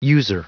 Prononciation du mot user en anglais (fichier audio)
Prononciation du mot : user